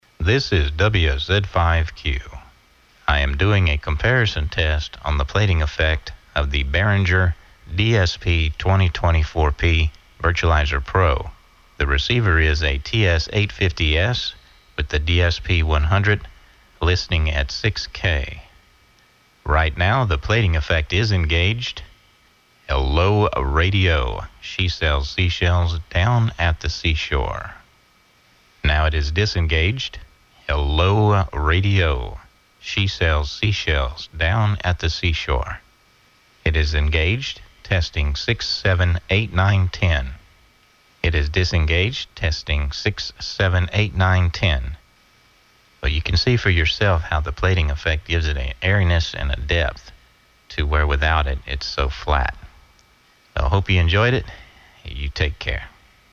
WET AUDIO
We use a variation of Plating that gives the Audio such a subtle Airiness and Depth. It's very transparent and you wouldn't notice it unless an A - B comparison was given.